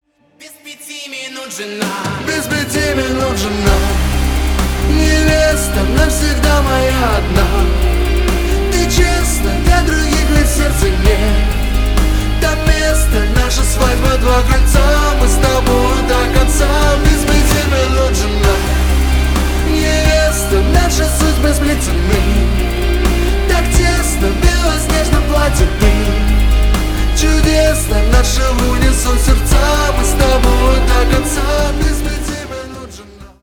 • Качество: 320, Stereo
свадебные
нежные